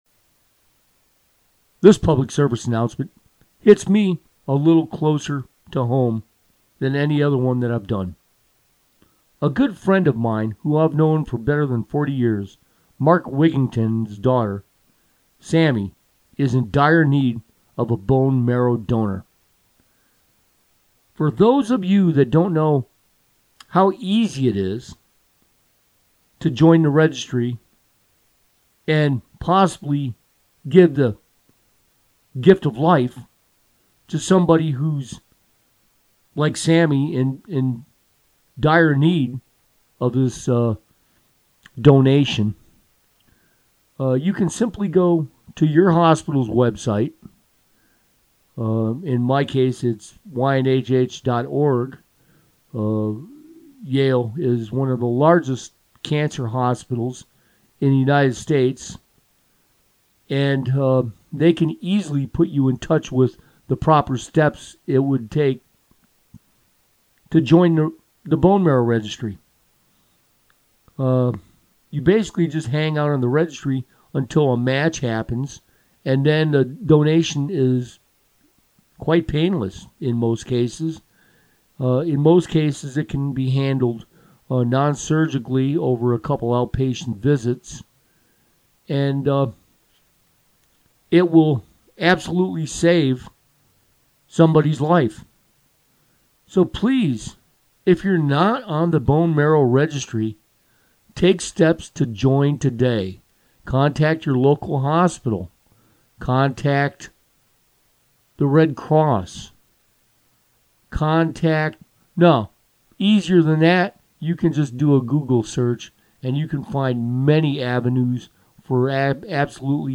PSA – Bone Marrow Registry